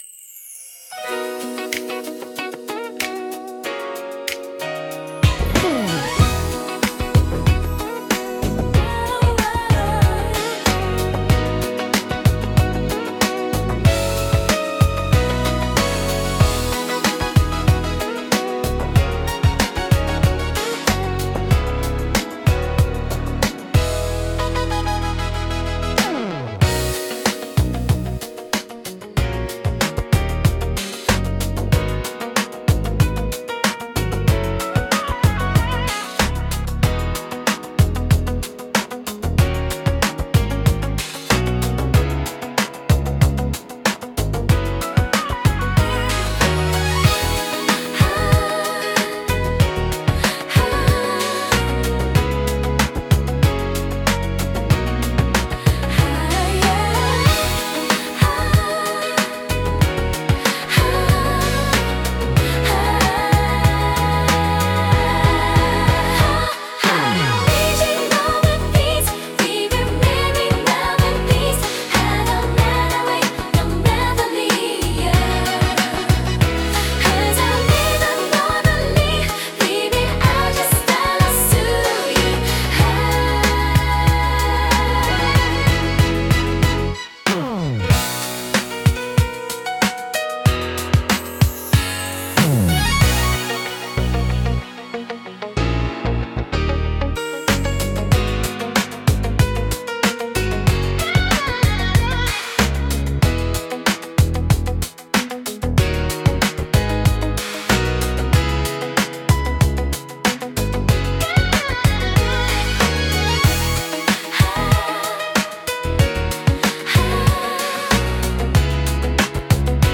聴く人に心地よいリズム感と温かみを届ける、優雅で感性的なジャンルです。